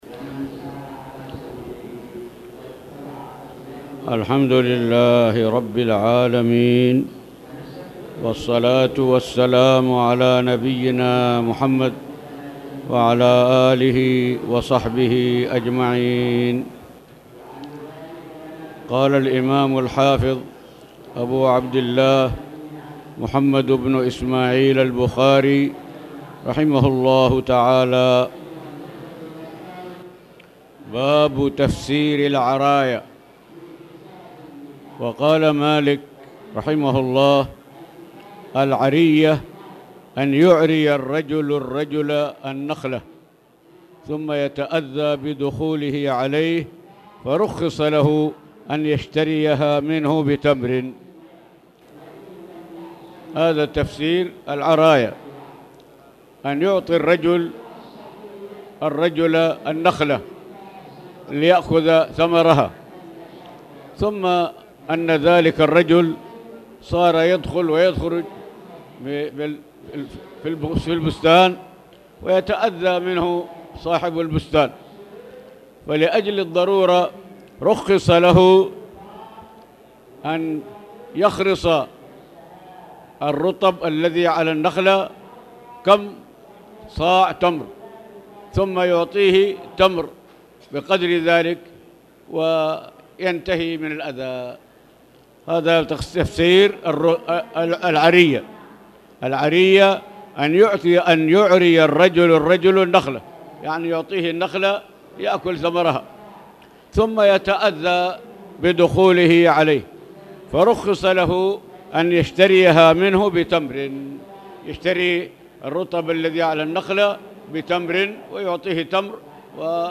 تاريخ النشر ٤ جمادى الأولى ١٤٣٨ هـ المكان: المسجد الحرام الشيخ